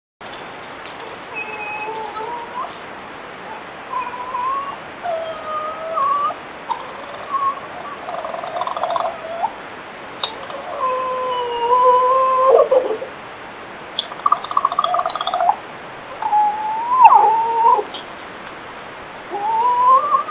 Interaction between a submissive vixen (under trampoline) and a dog fox in a garden in West Sussex in February 2025. This captures the churring/clackering "growl" that's rarely heard from foxes, here being uttered by the unimpressed male. The vixen remains prostrate, with her mouth open and makes the whining noises. Amplified from the video above.